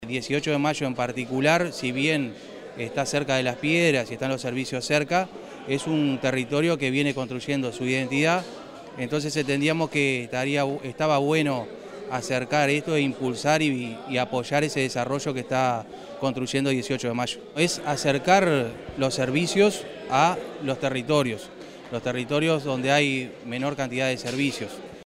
El Gobierno de Canelones y el Correo Uruguayo realizaron la inauguración del Centro de Cercanía de 18 de Mayo, ubicado en la calle Av. Maestro Julio Castro esquina Solís.